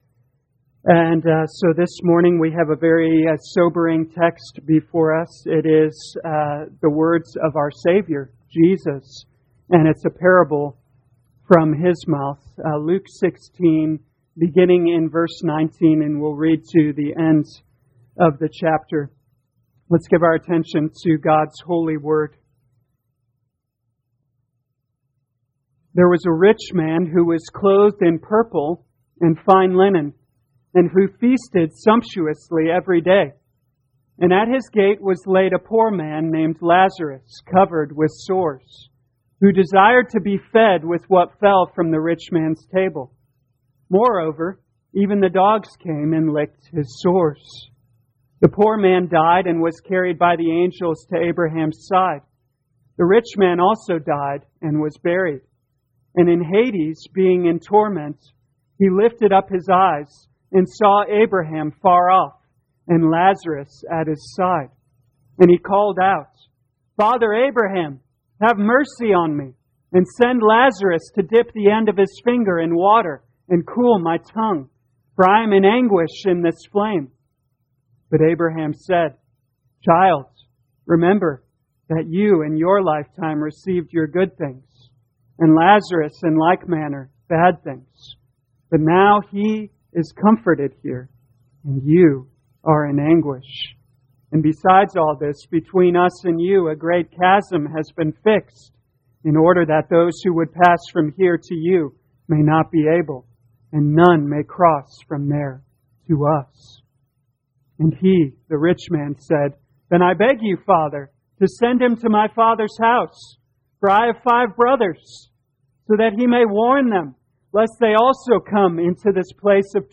2021 Luke Humility Morning Service Download
You are free to download this sermon for personal use or share this page to Social Media. Pride's Final Destination Scripture: Luke 16:19-31